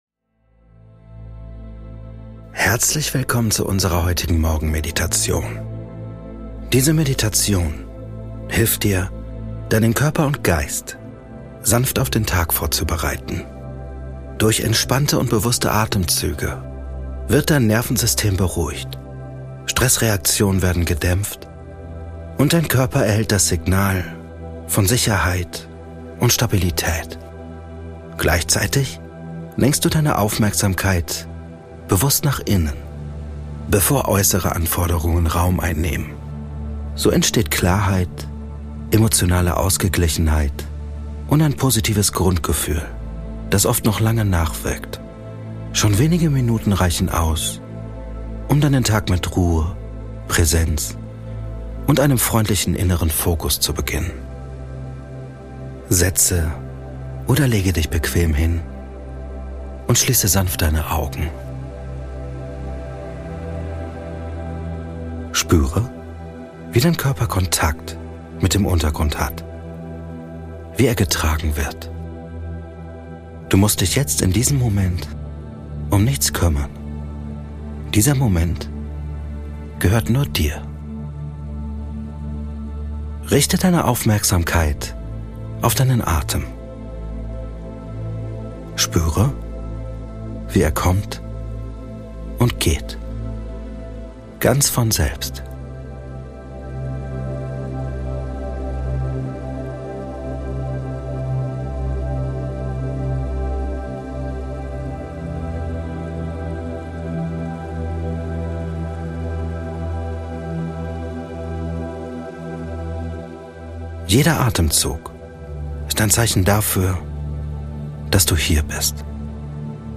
Diese geführte Meditation am Morgen unterstützt dich dabei, den Autopiloten zu verlassen und mit mehr Gelassenheit, Fokus und innerer Klarheit in den Tag zu starten.